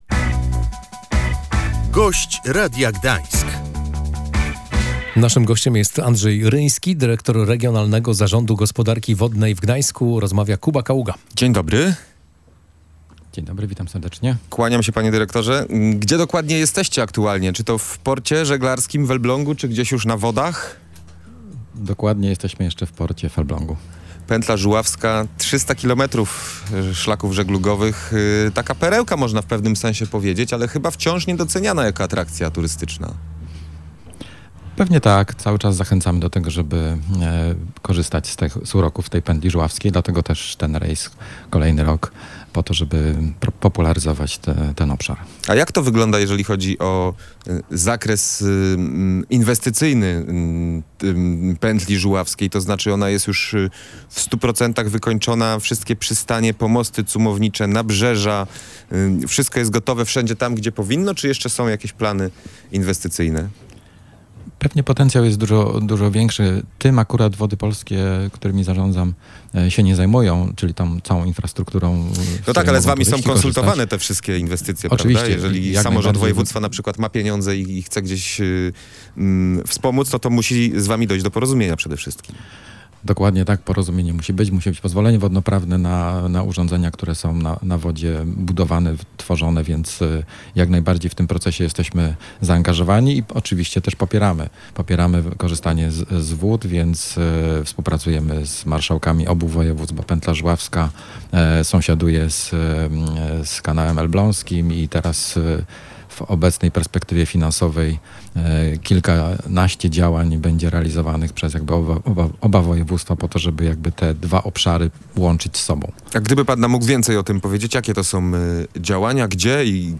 Jak wskazywał na antenie Radia Gdańsk dyrektor Regionalnego Zarządu Gospodarki Wodnej w Gdańsku Andrzej Ryński, hydrologom coraz trudniej walczy się ze skutkami suszy.